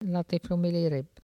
Localisation Saint-Jean-de-Monts
Langue Maraîchin
Catégorie Locution